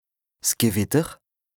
L’alsacien regroupe plusieurs variantes dialectales du Nord au Sud de l’Alsace.
Nous avons tenté d’être représentatifs de cette diversité linguistique en proposant différentes variantes d’alsacien pour chaque lexique, à l’écrit et à l’oral.